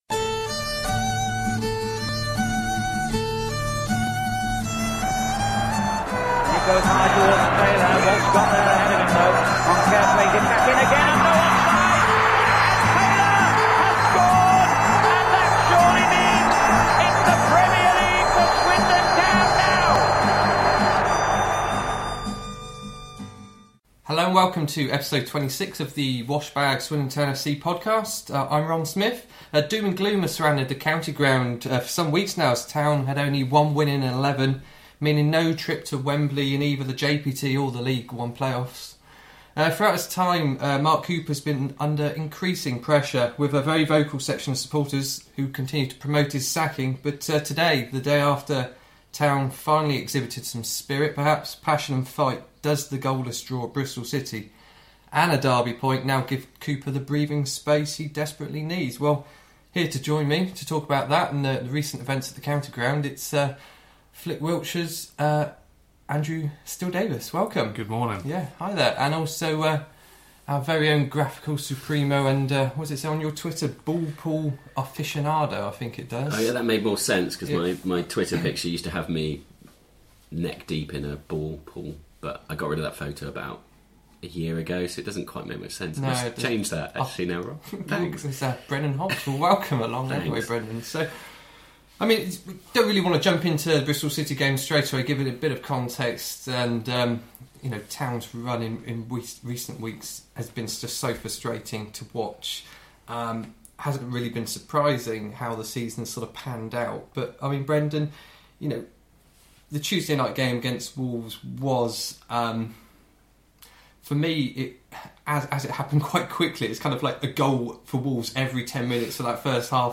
The three look back at the Wolves defeat and ask how it will affect Town’s final ten game of the season, a review of the Bristol City draw, Alex Pritchard’s sending-off, Mark Cooper’s future and more! Apologies for the cough, I managed to edit out all two but coughing fits!